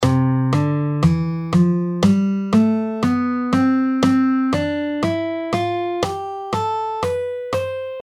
C Ionian (C Major)
C Ionian (C Major): C – D – E – F – G – A – B – C. The classic major scale, characterized by its bright and jubilant sound.